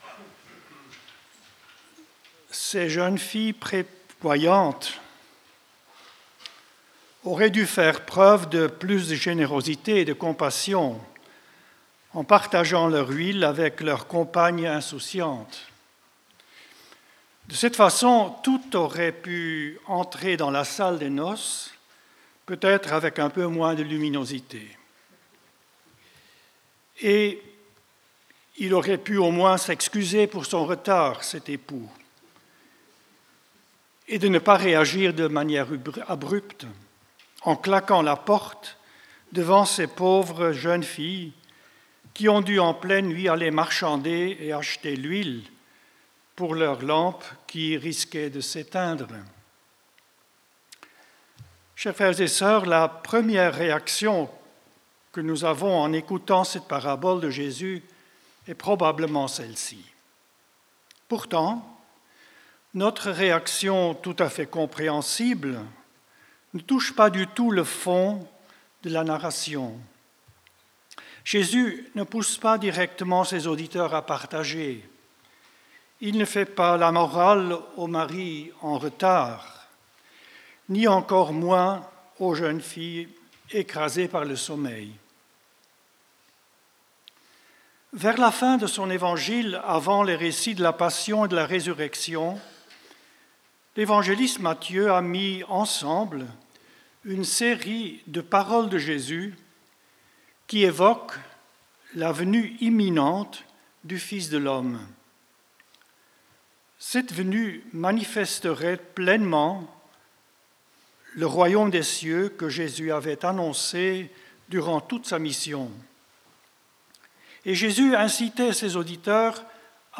Pour ce dimanche de la 32ème semaine du Temps Ordinaire